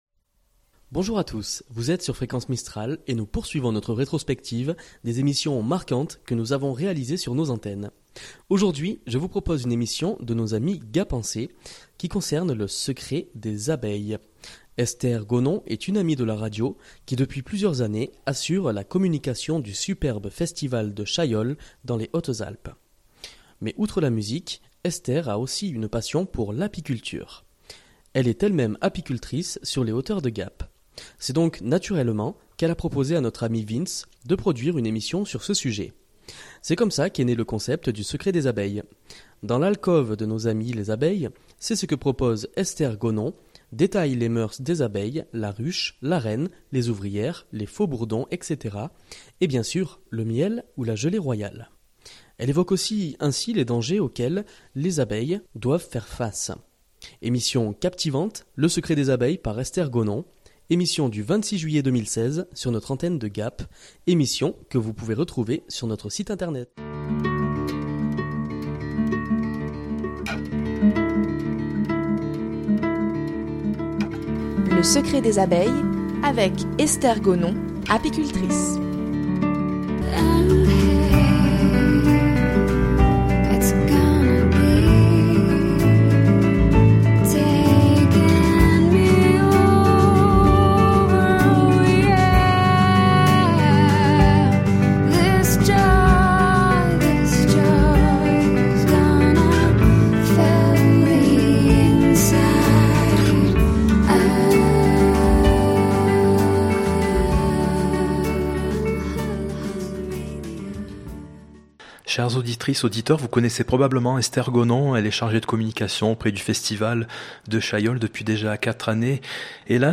Pendant cette période de fêtes Fréquence Mistral vous propose des rediffusions d'émissions qui auront marqué l'année qui s'achève.